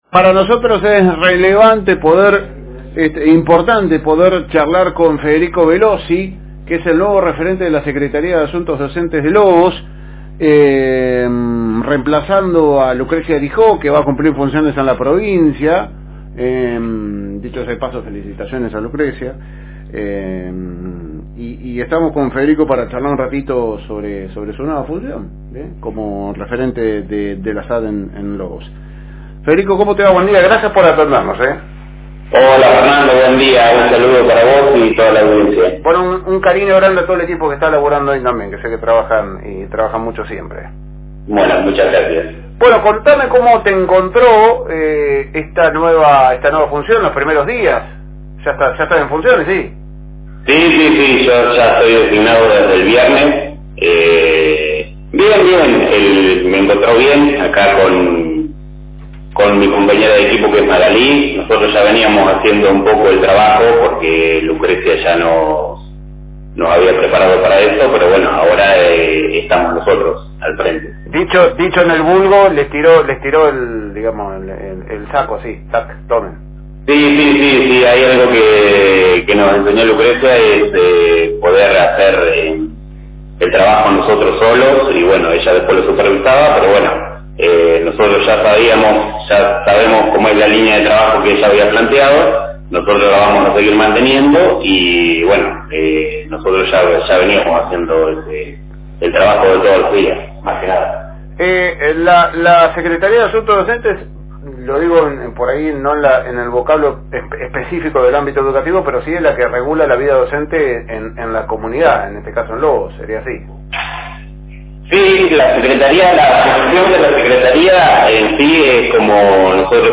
La información fue ampliada durante una entrevista telefónica exclusiva con FM Reencuentro 102.9